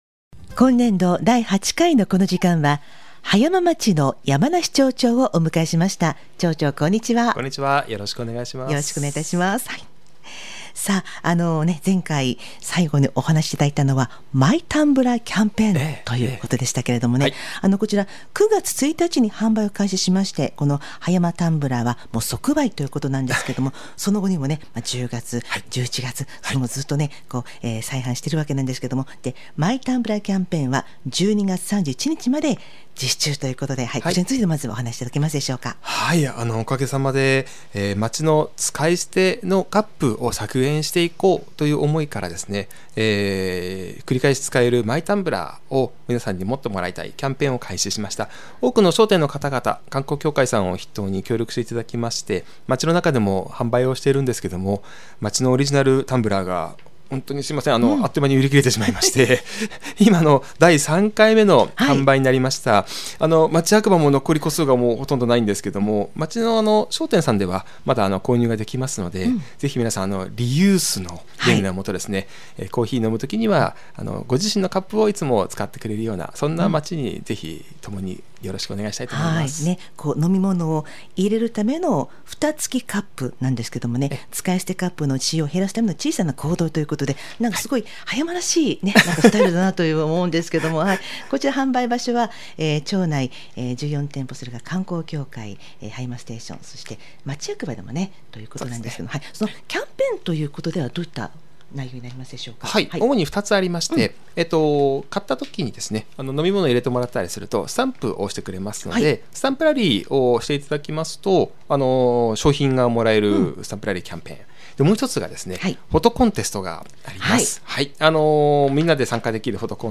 2025-11-29(土) 放送 葉山町山梨崇仁町長インタビュー
市町長県議インタビュー